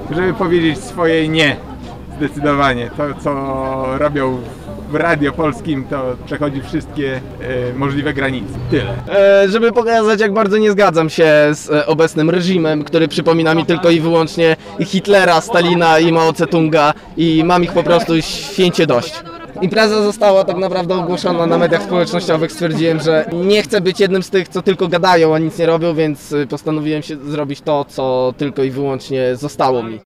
Jak swój udział w happeningu tłumaczyli ełczanie?